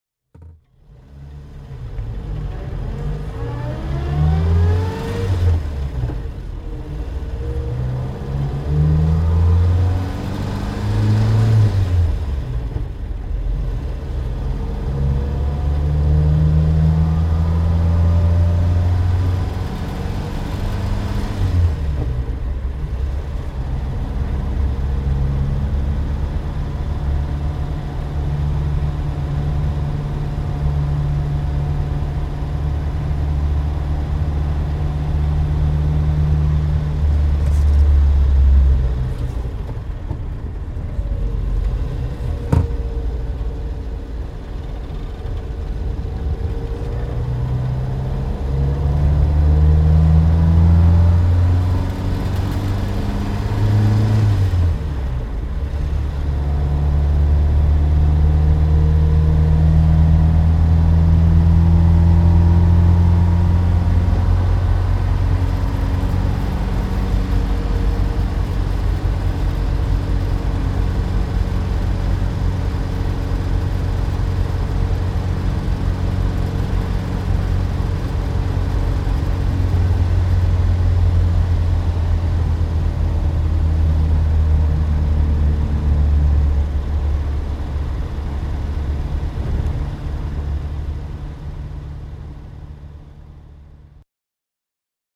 Simca Aronde Plein Ciel (1960) - Fahrgeräusch
Simca_Aronde_Plein_Ciel_1960_-_Fahrgeraeusch.mp3